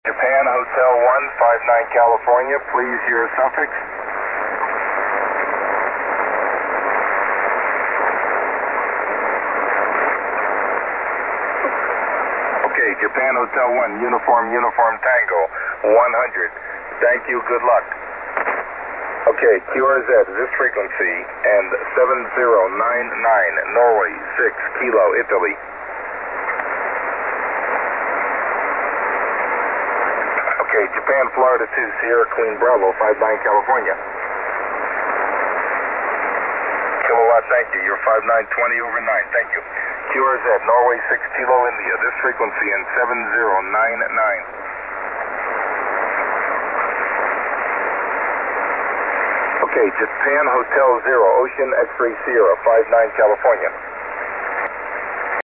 You will not hear the stations in Japan as I was working split.
SWL Report: Band: 40m, RS: 59 + 20dB Rig:IC-756pro2 Ant: Simple vertical ant QRM. QSB: None   I was so amazed that you were as strong as my local hams.